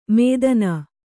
♪ mēdanā